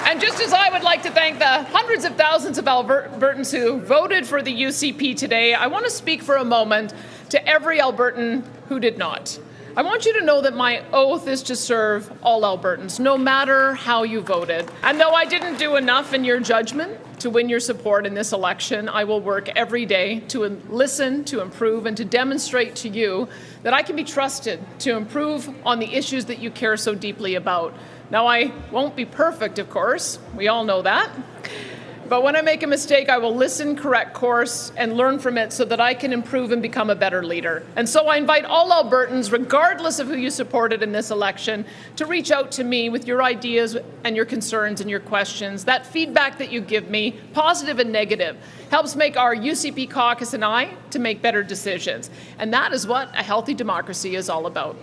Smith responded to Notley’s call to represent all Albertans in her victory speech.
ucp-victory-speech.mp3